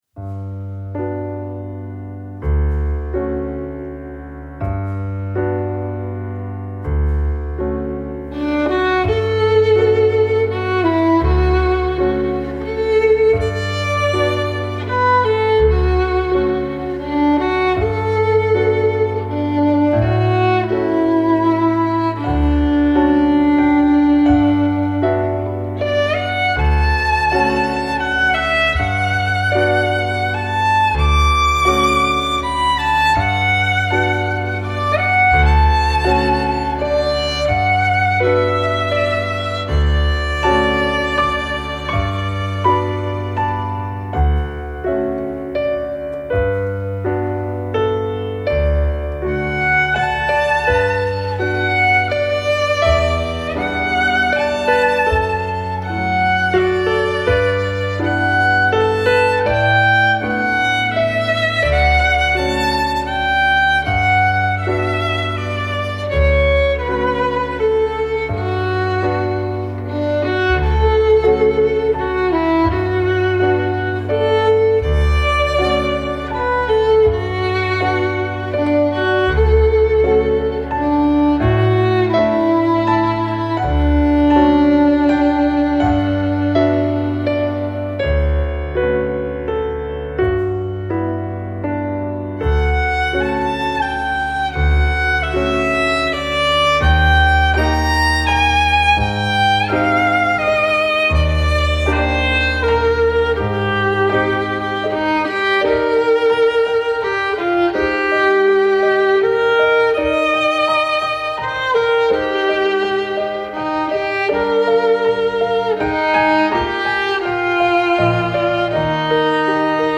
Advanced Violin Solos for Thanksgiving